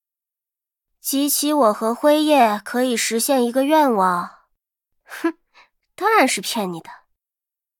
尘白禁区_安卡希雅辉夜语音_共鸣.mp3